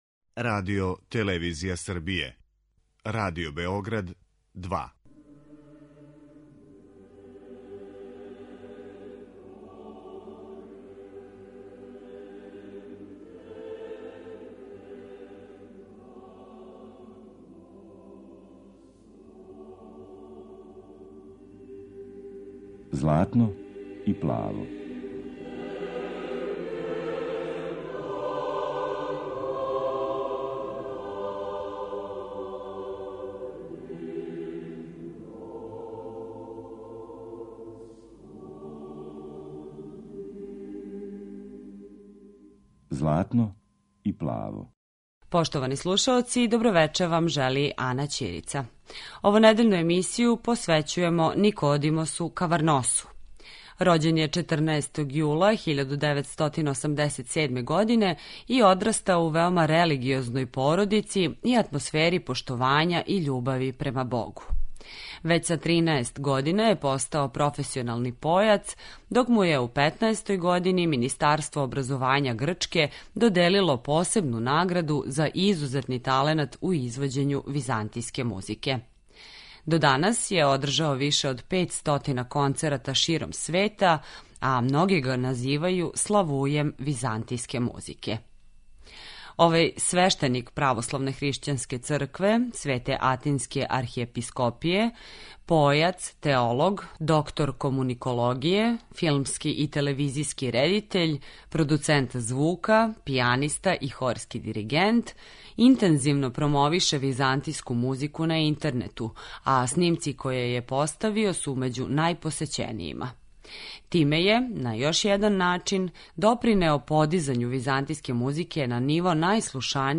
Славуј византијске музике
Емисија посвећена православној духовној музици.